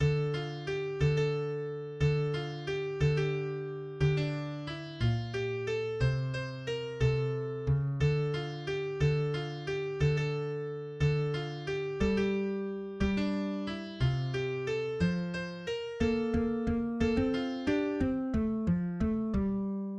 \new Staff  \with {midiInstrument = #"acoustic bass"}